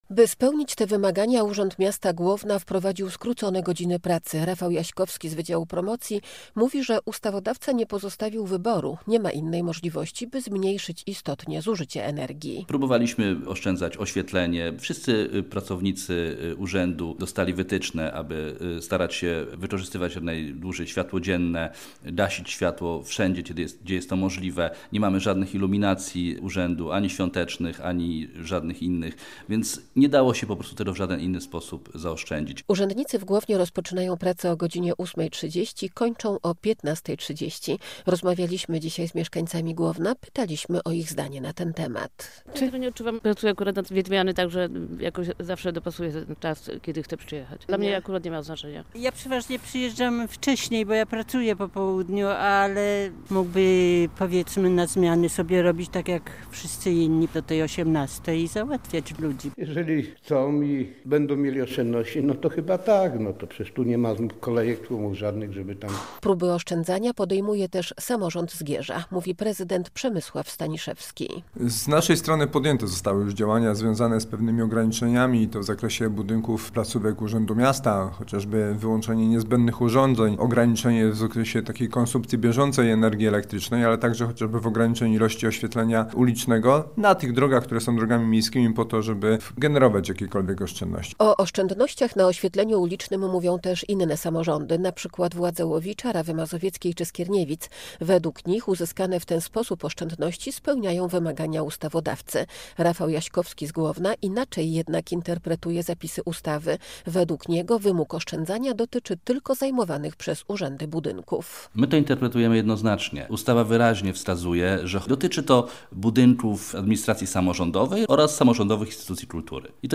Urzędnicy w Głownie rozpoczynają pracę o godzinie 8.30 i kończą o godz. 15.30. O zdanie na ten temat pytaliśmy mieszkańców Głowna.